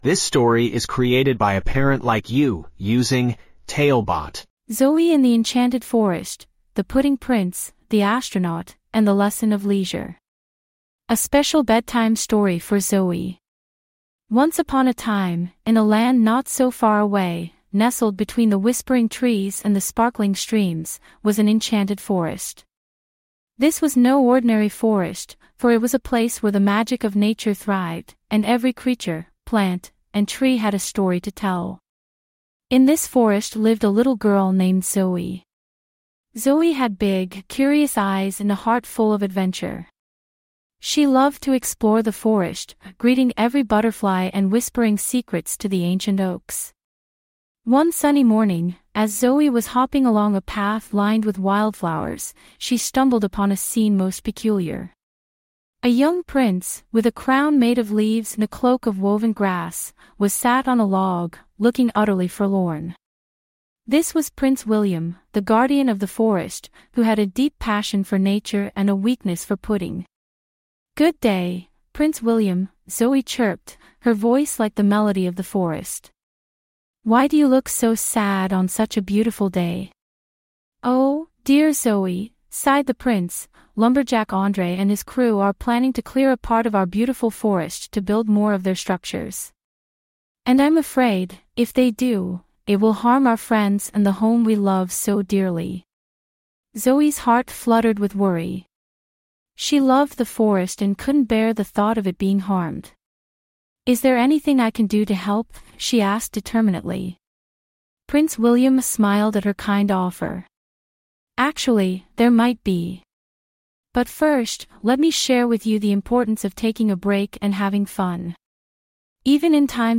5 minute bedtime stories.